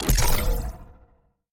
ui_click.mp3